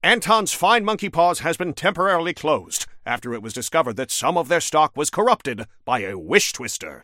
Newscaster_headline_70_02.mp3